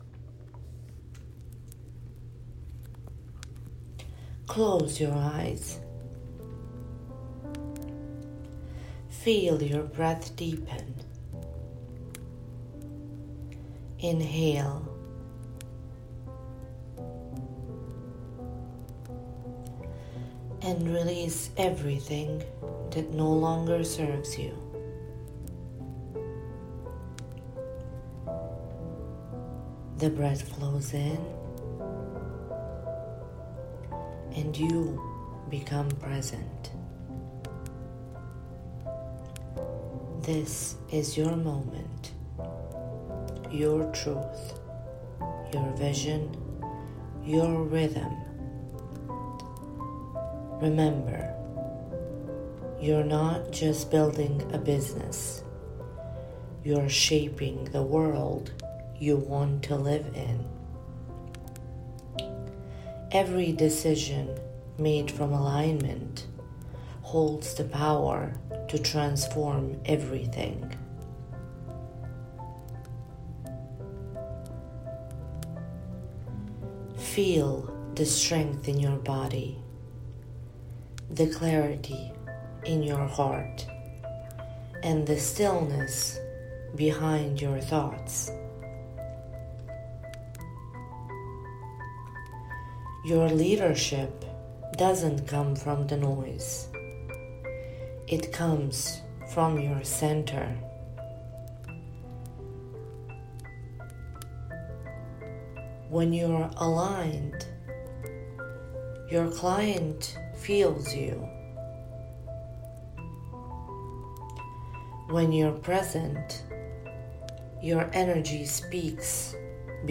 A guided reflection audio experience designed to help you reconnect with your inner wisdom and authentic leadership presence.